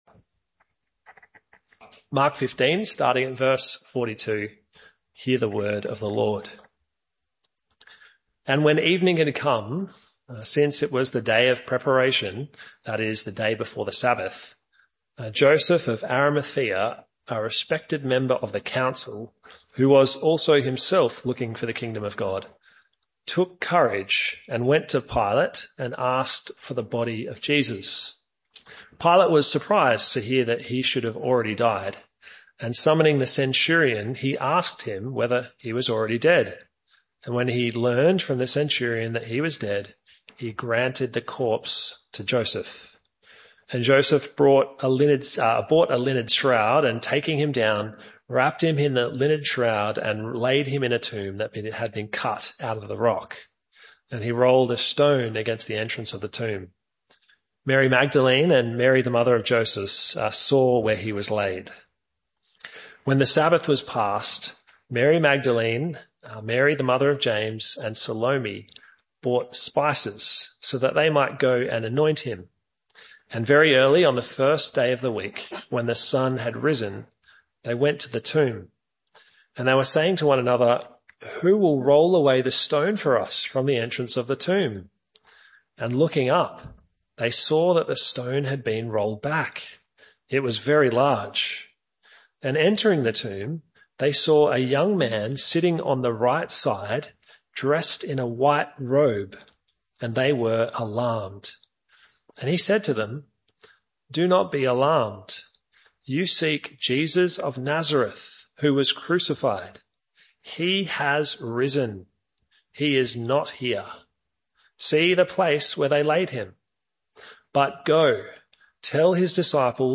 Mark 15:42-16:8 Service Type: Evening Service This talk was part of the AM/PM series entitled The Way Of The Cross.